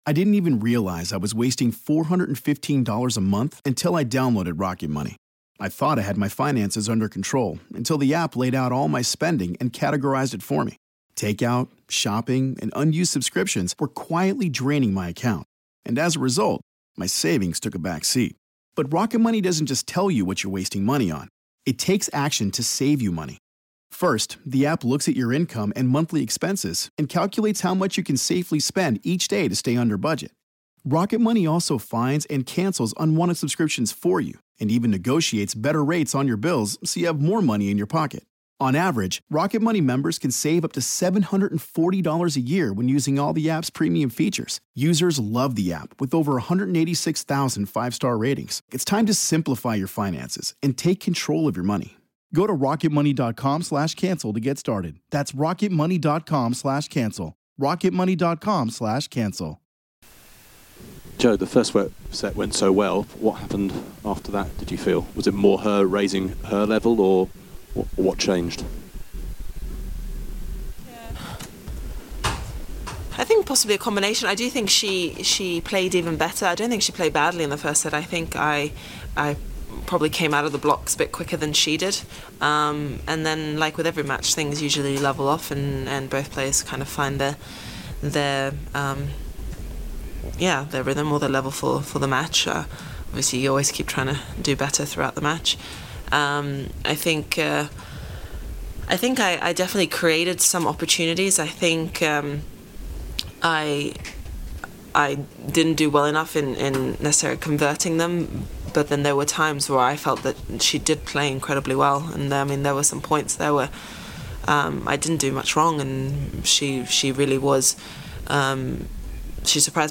Here's Jo after her 6-1, 6-7, 4-6 1st round loss at Roland-Garros vs Su-Wei Hsieh